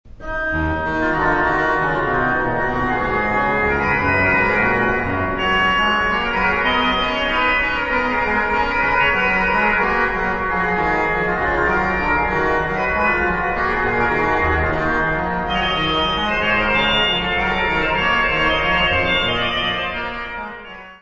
Organ works
Notas:  Este disco foi gravado ao vivo na Basilica de St. Alexander und Theodor, Benediktinerabtei em Ottobeuren na Alemanha, durante o mês de Maio de 1998; O orgão utilizado na gravação foi construído por Johann Andreas Silbermann, entre 1756-1761; No do Serviço de Aquisições e Tratamento Técnico